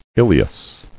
[il·e·us]